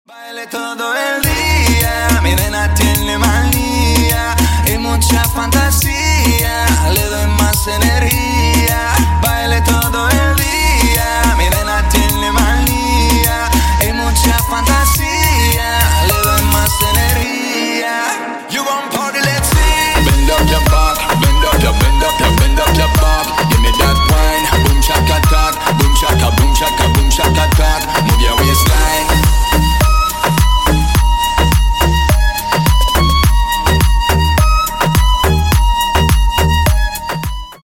Клубные Рингтоны » # Латинские Рингтоны
Танцевальные Рингтоны